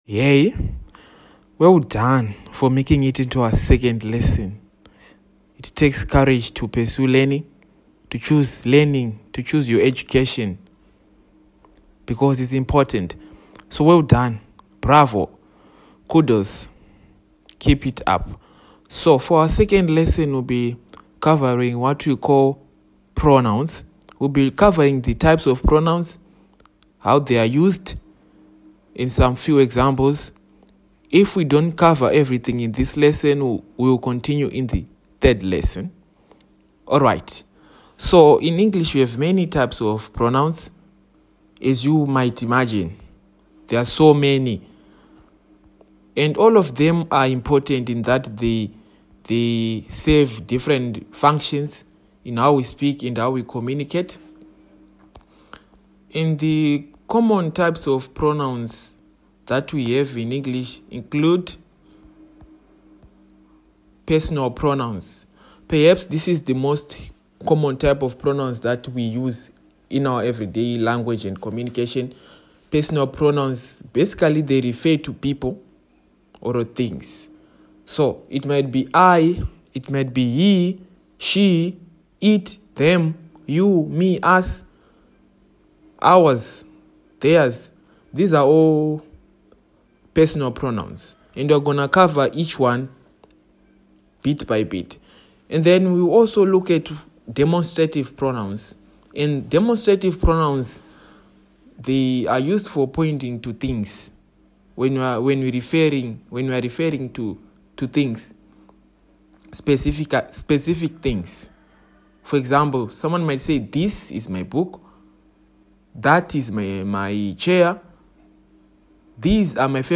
An audio walkthrough of the eight types of pronouns with examples and brief descriptions for each.
lesson-3-pronouns-8-types.mp3